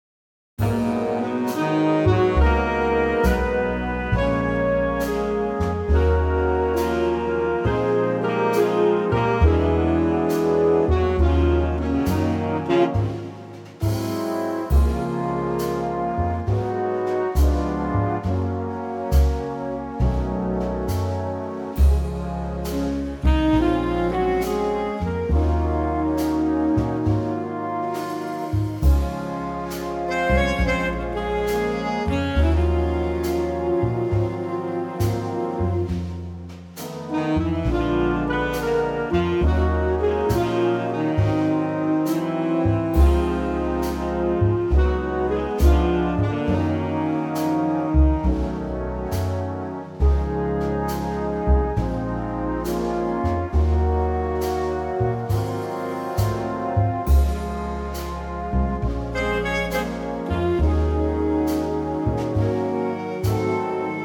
key - Db - vocal range - Ab to C
Stunning mellow Big Band arrangement